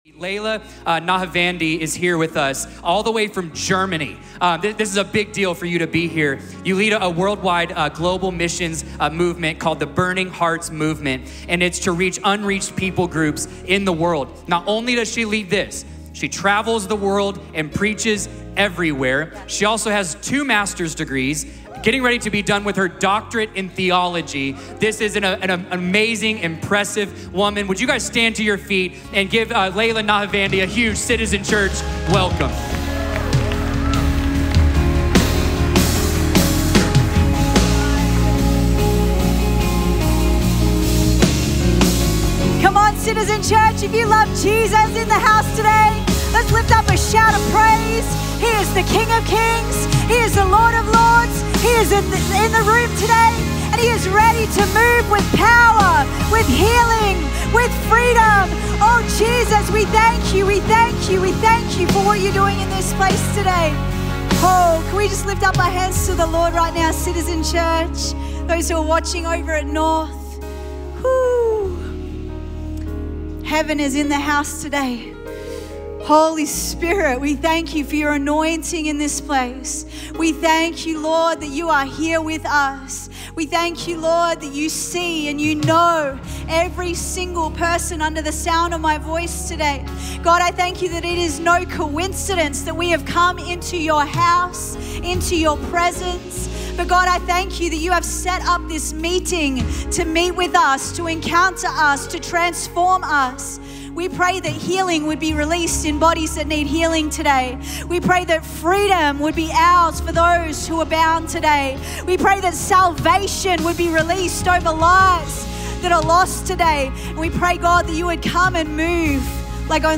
A message from the series "Guest Speakers."